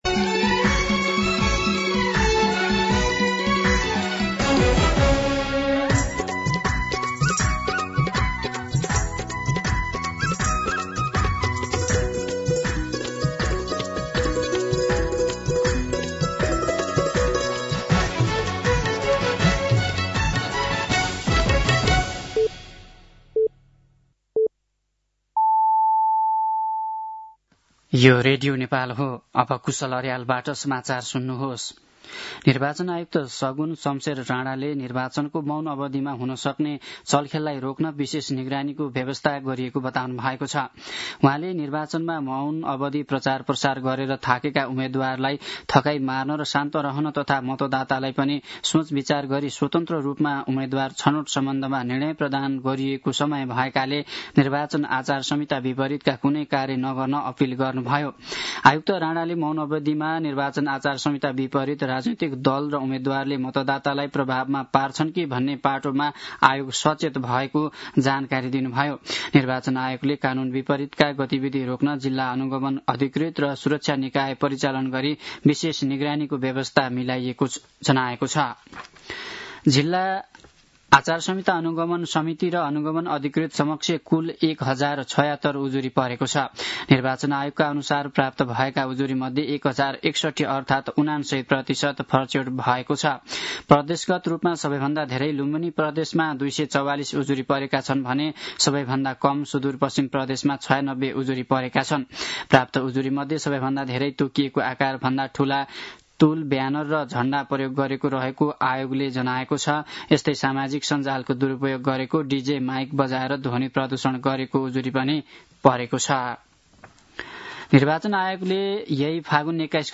दिउँसो १ बजेको नेपाली समाचार : १६ फागुन , २०८२